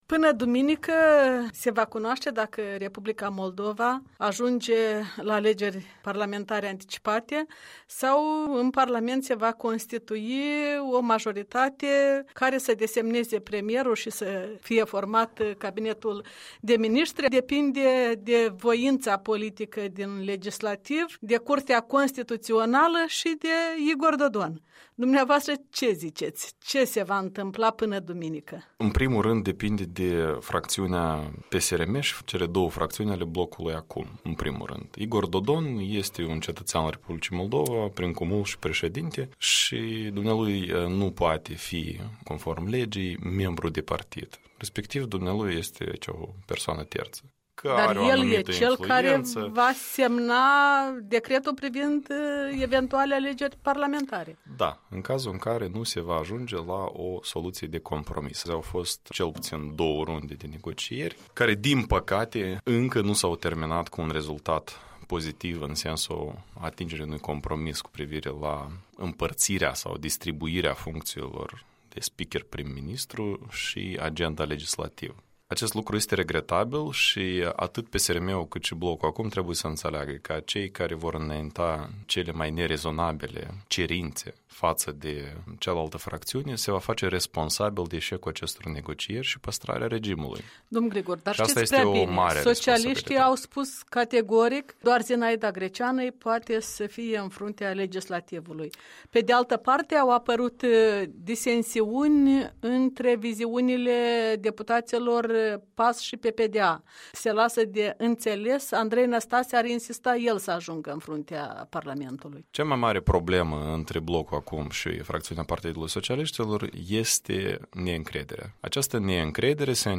Un interviu cu activistul civic, expert în politici publice, de la Chișinău.